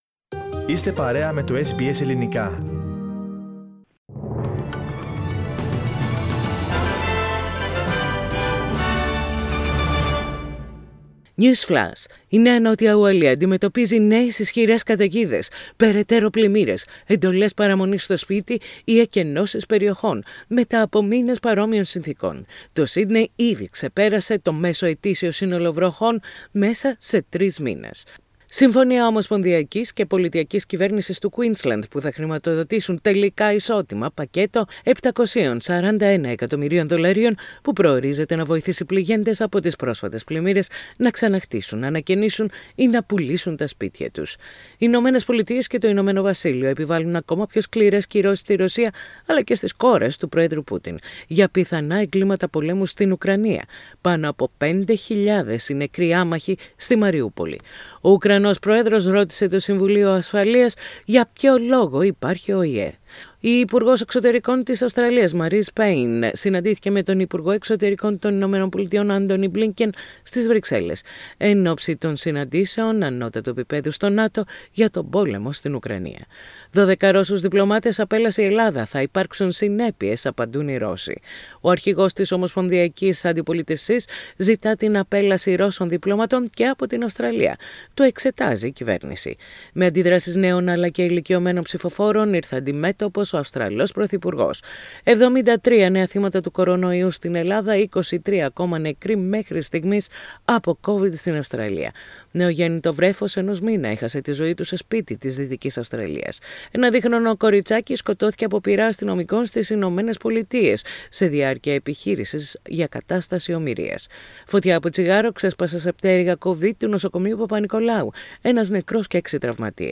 News Flash in Greek Source: SBS Radio